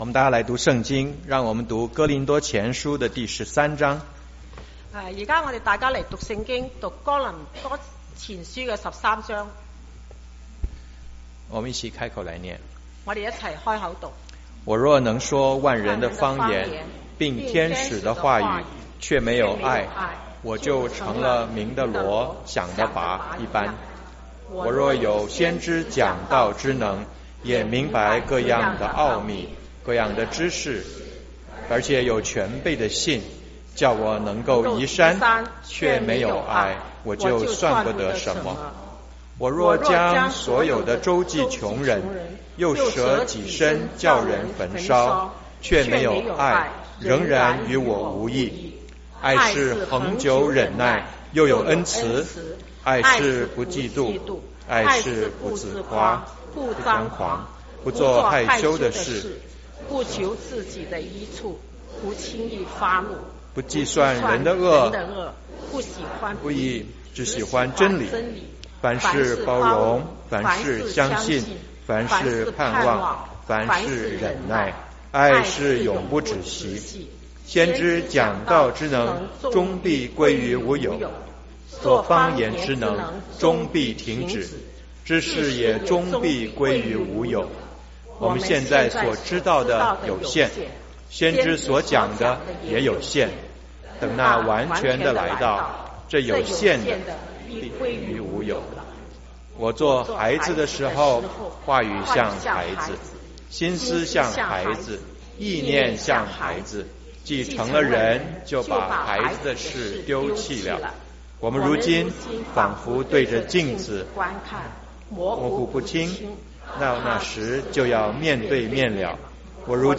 中文堂講道信息 | First Baptist Church of Flushing